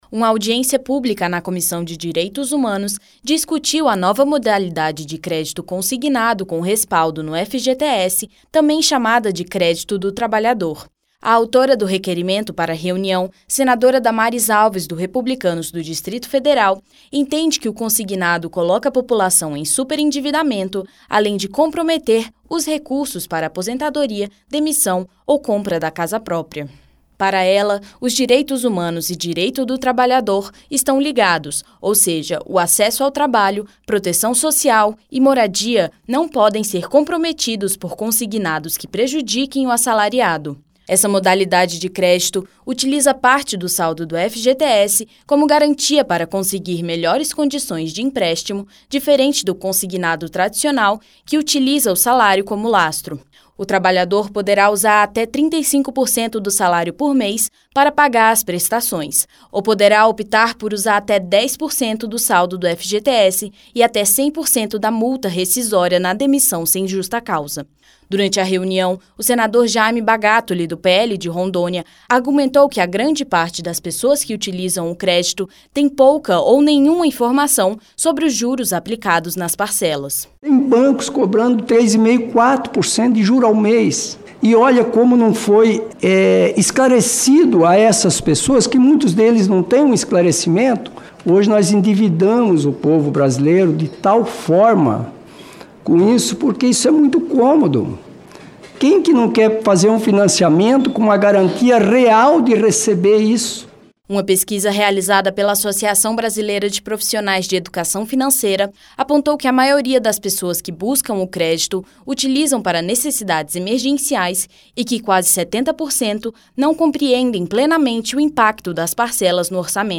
Em audiência pública na Comissão de Direitos Humanos (CDH), parlamentares e especialistas debateram a nova modalidade de crédito consignado com garantia no Fundo de Garantia do Tempo de Serviço (FGTS), conhecida como Crédito do Trabalhador. A senadora Damares Alves (Republicanos-DF), autora do requerimento para a reunião, manifestou preocupação com o risco de superendividamento da população.